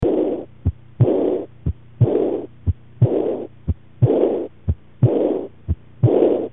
Hartgeluiden
Meestal is het geluid van S1 ook luider, langer en van een lagere frequentie dan S2.
Stenose van de aorta veroorzaakt een hard systolisch ruizen.